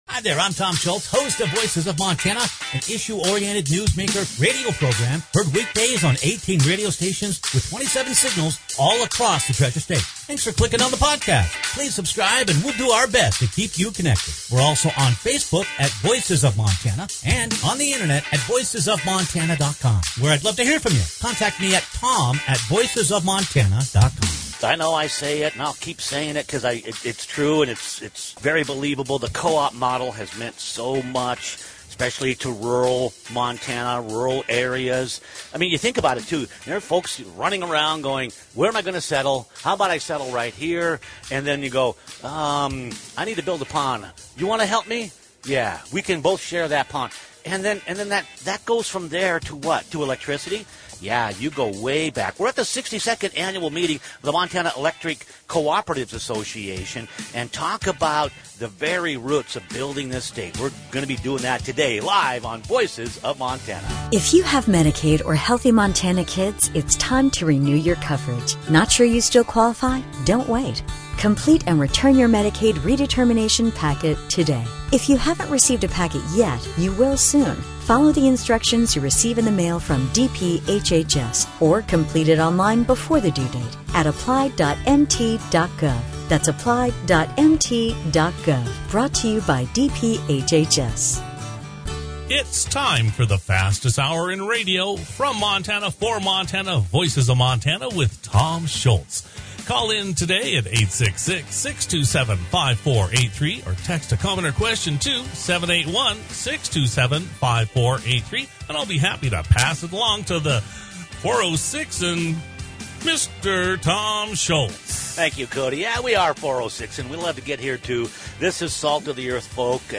Growth, EPA Overreach, Youth Opportunities, and Resource Availability. Those are the major themes of the broadcast from the 62nd Annual Montana Electric Cooperatives' Association convention in Great Falls.